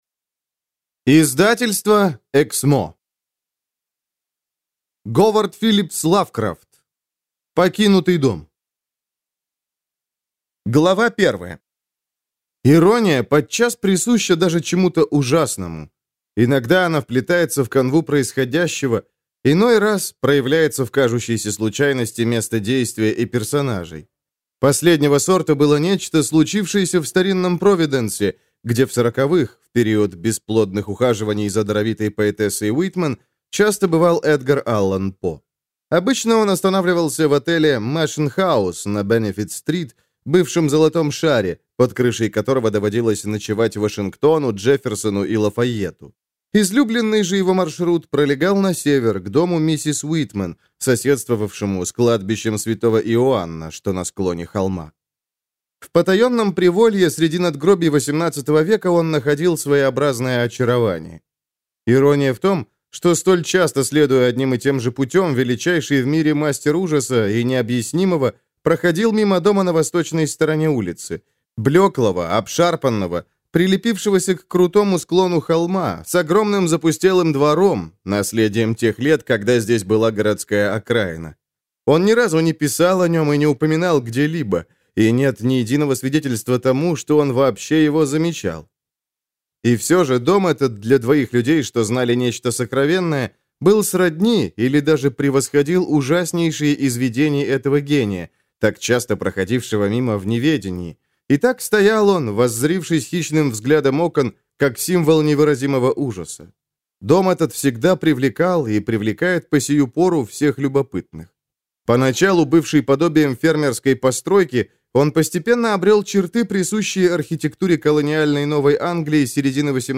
Аудиокнига Покинутый дом | Библиотека аудиокниг